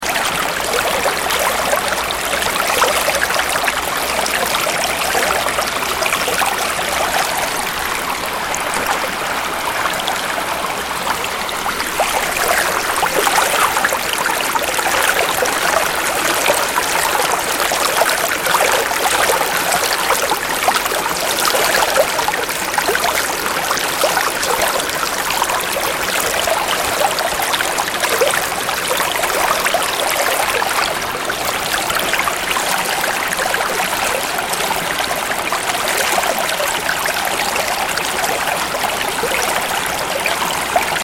Water Stream 1
water-stream-1.mp3